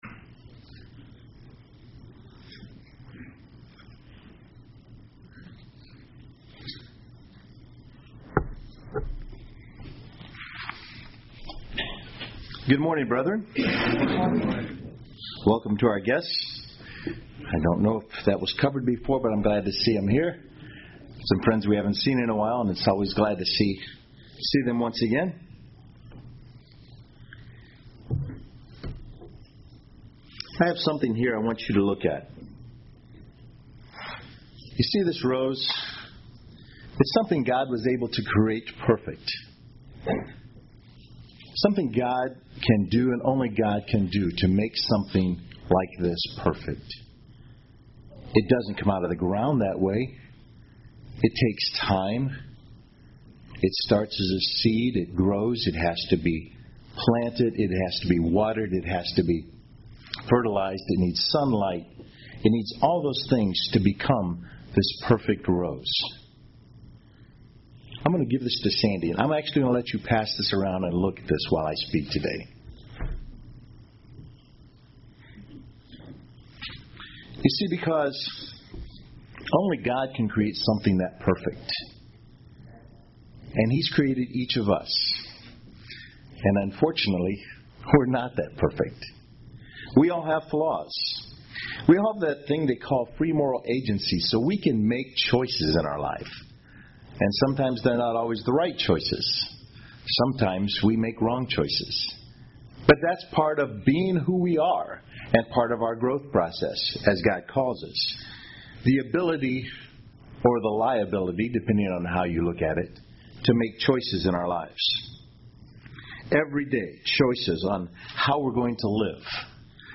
UCG Sermon Studying the bible?
Given in Murfreesboro, TN